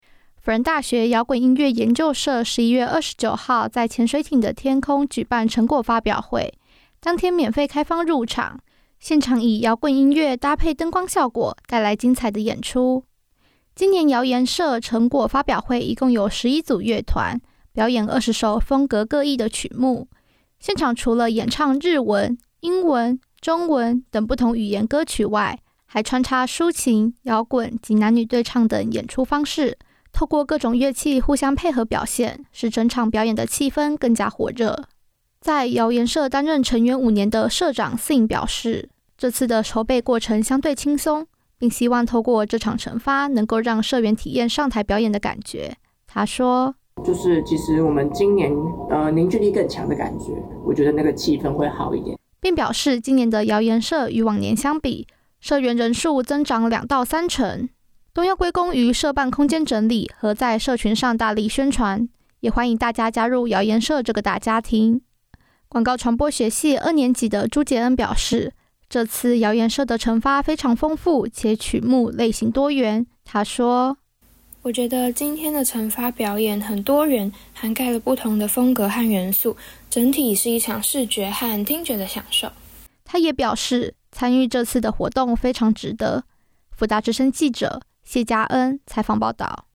採訪報導